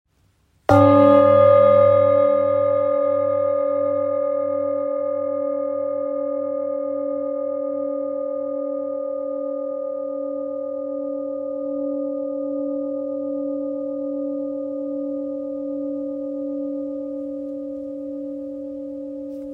Authentic Ancient Seven Metal Bowl – 20.5cm
The rich, ancient tones reach into the soul, calming the mind and unlocking inner peace.
Its authenticity is evident in every detail, from its textured surface to its resonant sound, which carries the wisdom of the ages.
Ancient-bowl.mp3